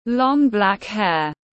Long black hair /lɒŋ blæk heər/